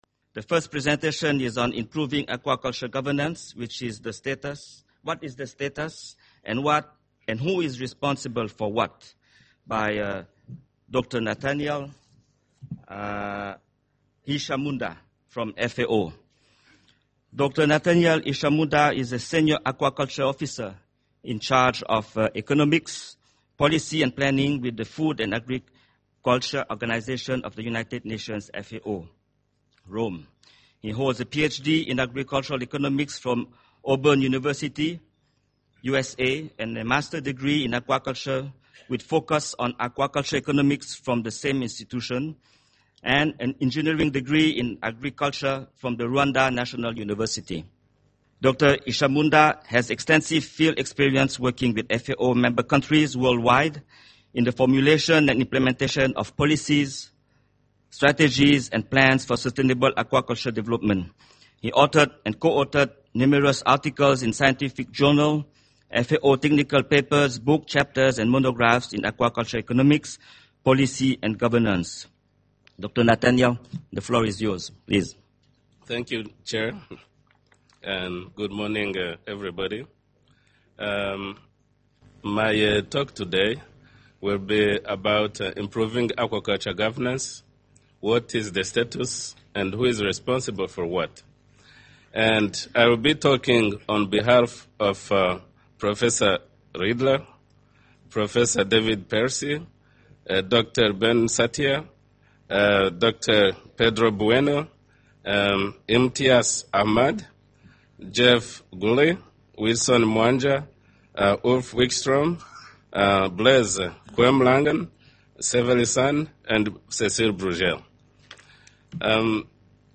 Presentation on the current state of aquaculture governance